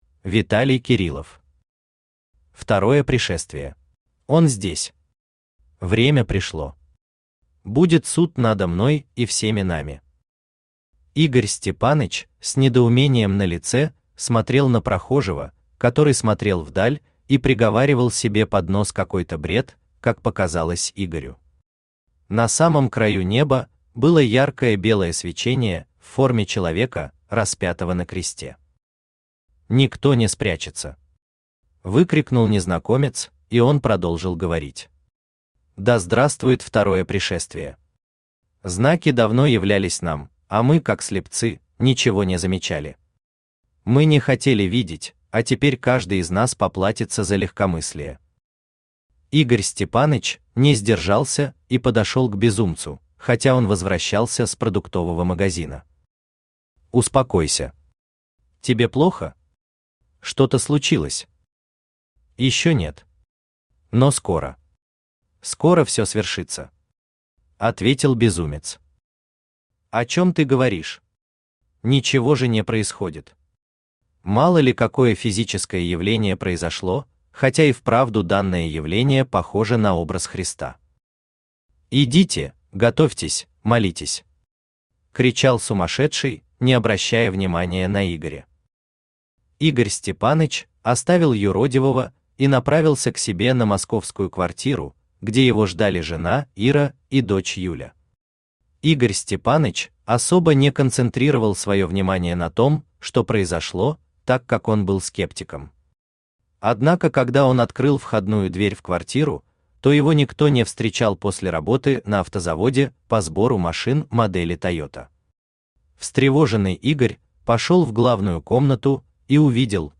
Аудиокнига Второе пришествие | Библиотека аудиокниг
Aудиокнига Второе пришествие Автор Виталий Александрович Кириллов Читает аудиокнигу Авточтец ЛитРес.